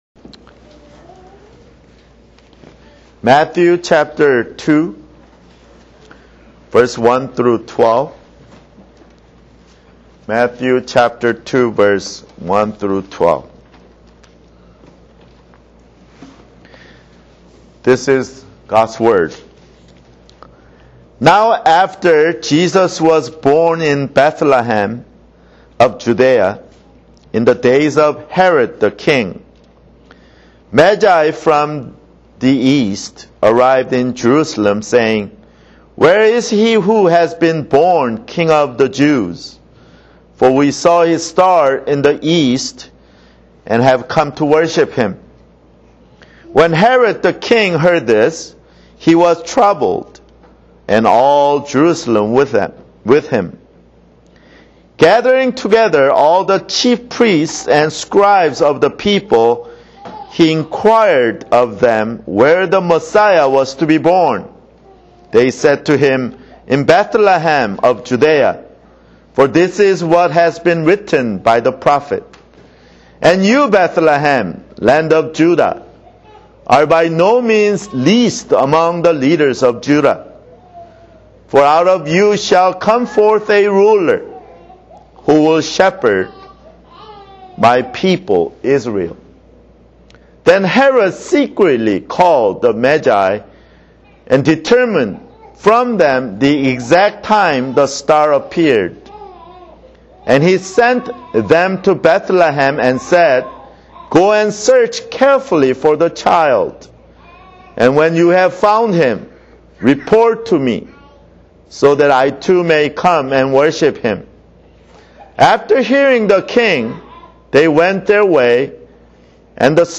[Sermon] Matthew (5)